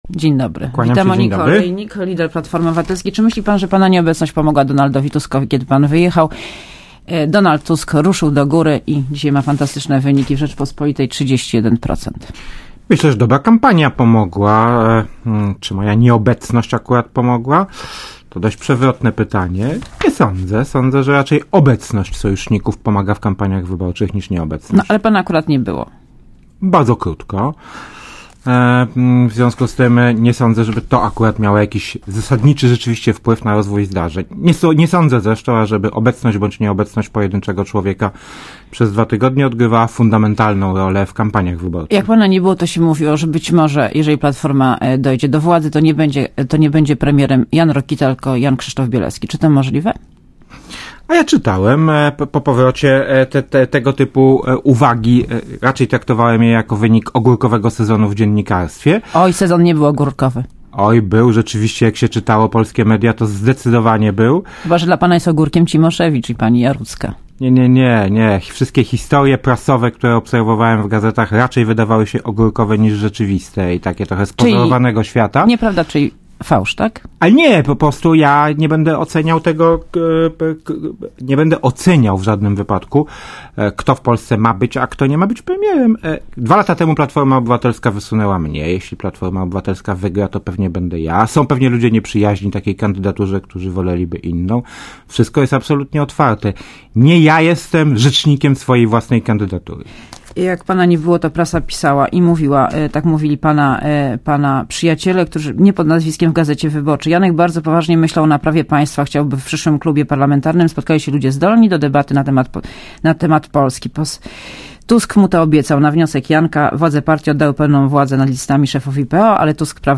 Jan Rokita w Radiu Zet (PAP)